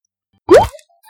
Всплеск